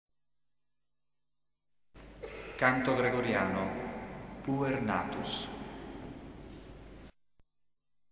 Elevazioni Musicali > 1995 > 1999
S. Alessandro in Colonna